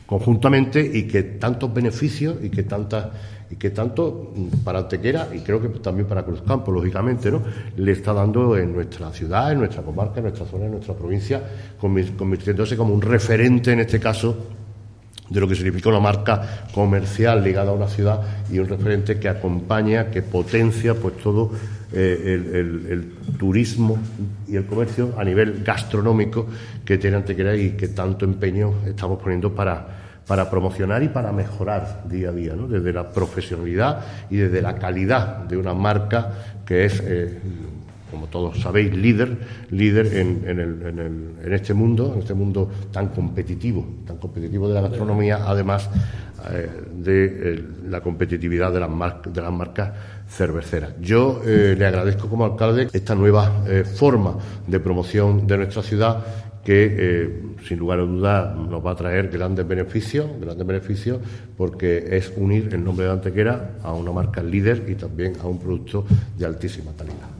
Cortes de voz
Presentacion de la campaña de Cruzcampo por el alcalde de Antequer   461.25 kb  Formato:  mp3